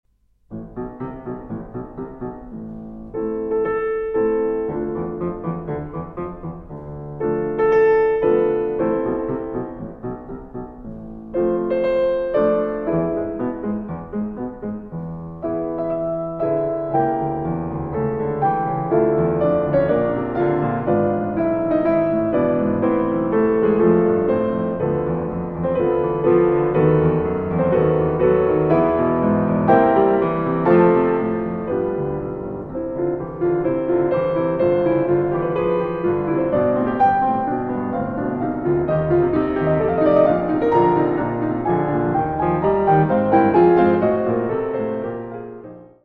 Allegretto sostenuto (3:14)